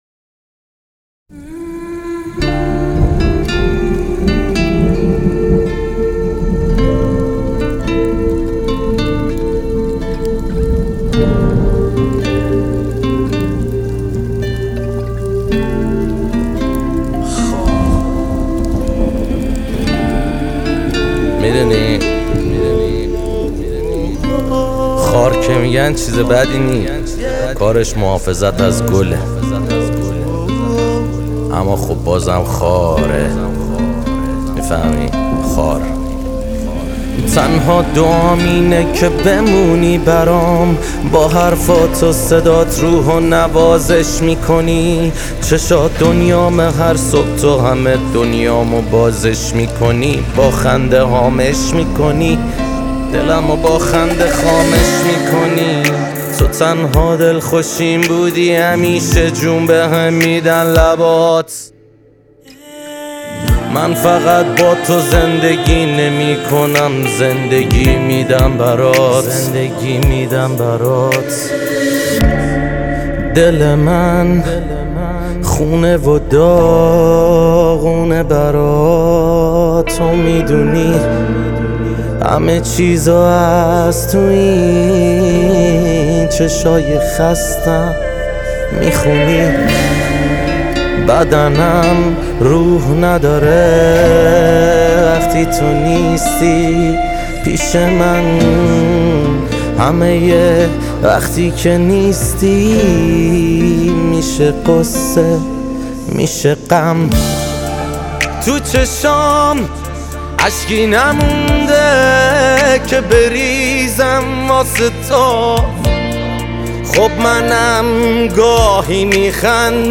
تک آهنگ
آراَندبی